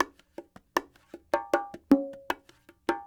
44 Bongo 01.wav